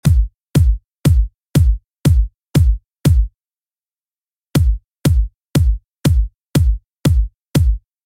Here is a before and after of my kick drum:
The difference is subtle, but you should be able to hear that the second set of kicks sound more punchy and controlled, with less ‘fluffyness’ to them – this will really help them sit in your mix.